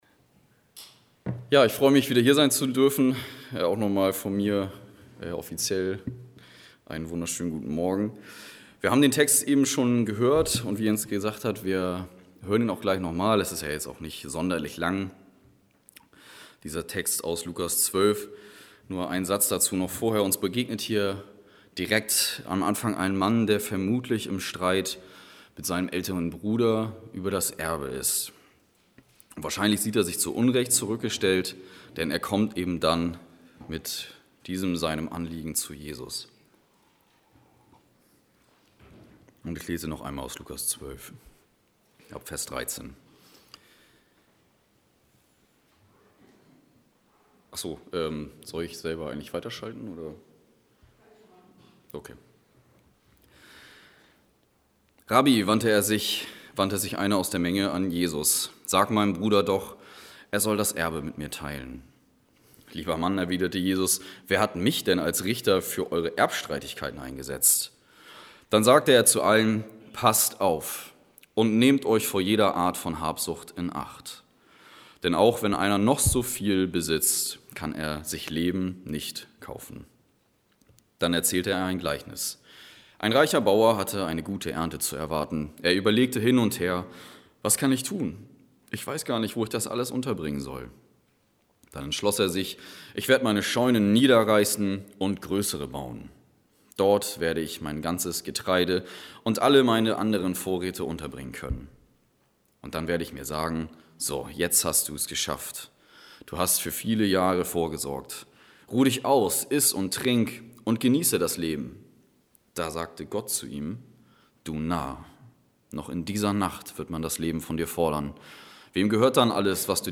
Passage: Lukas 12, 13-21 Kategorie: Gottesdienst « Heiligabend Gott hat uns nicht gegeben den Geist der Furcht